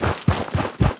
biter-walk-big-8.ogg